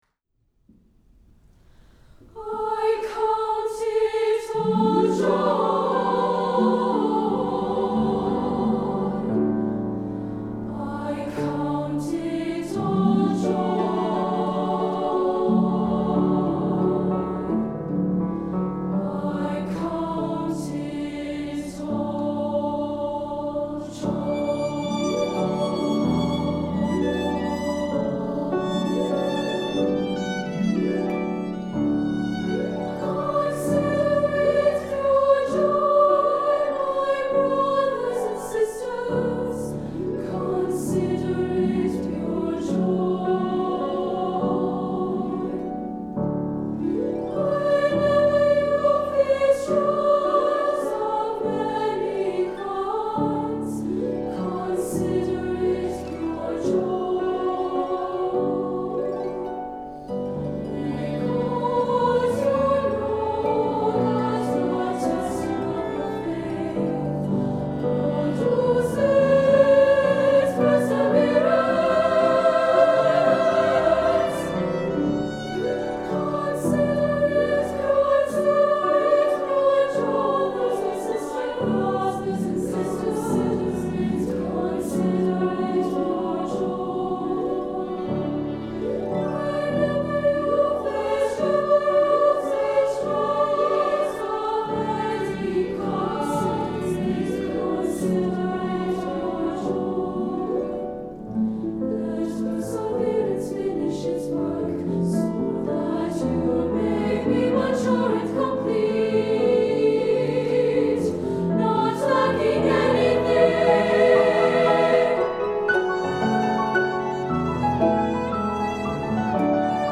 SSA choir, violin, harp, & piano